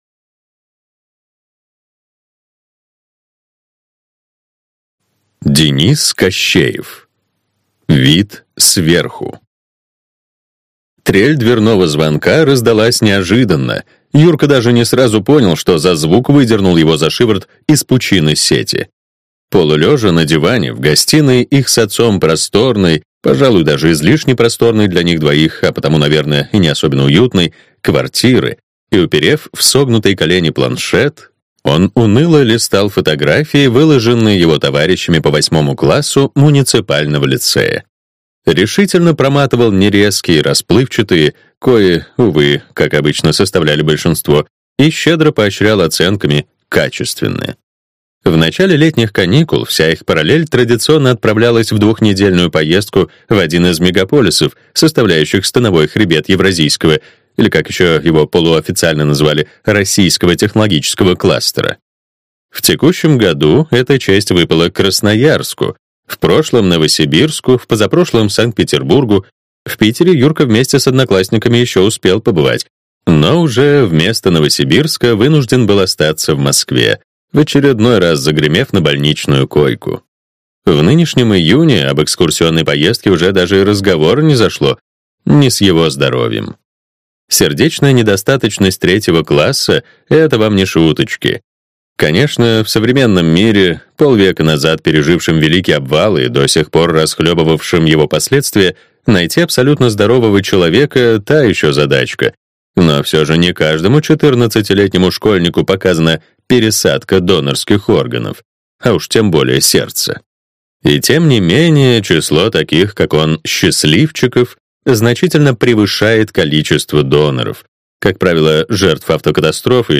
Аудиокнига Вид сверху | Библиотека аудиокниг